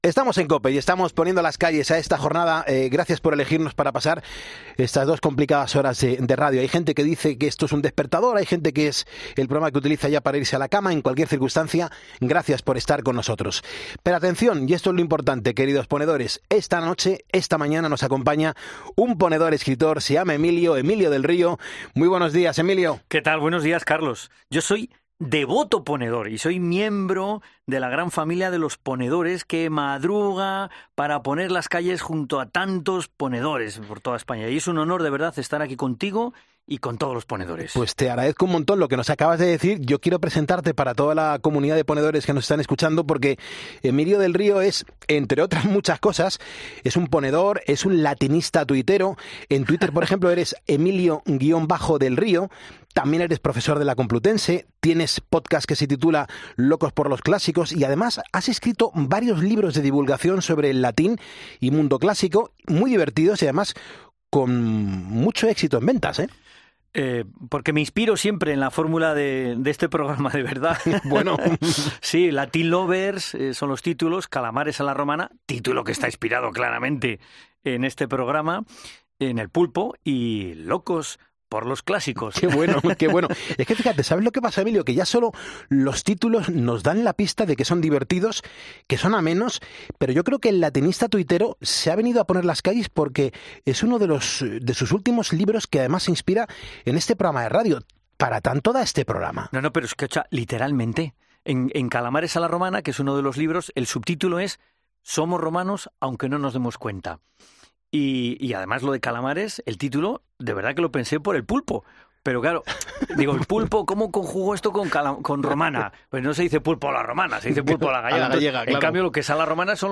Todos los secretos de la entrevista los puedes encontrar en el audio.